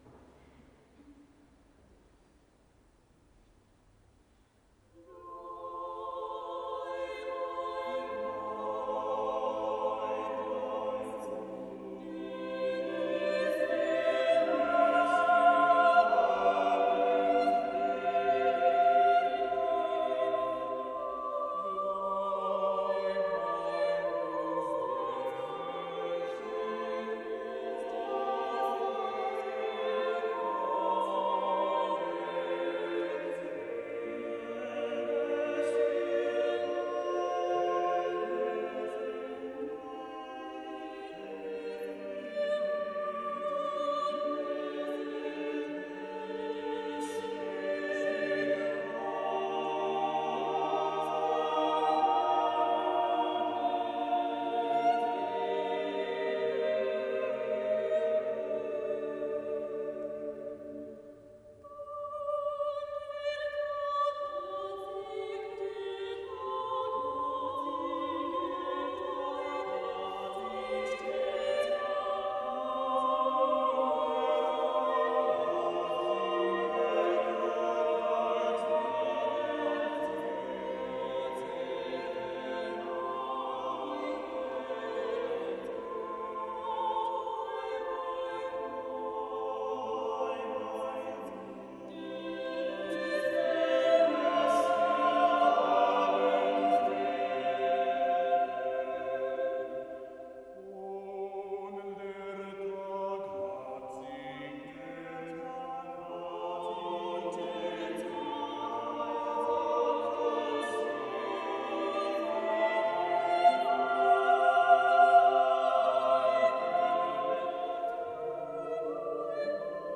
Vokální kvintet Ensemble Frizzante má za cíl objevovat zapomenuté skladby a nevšední propojení lidského hlasu s hudebními nástroji.
Josef Gabriel Rheinberger: Abendlied (živá nahrávka šestihlasu z koncertu 31.3.2016 v Červeném kostele v Brně)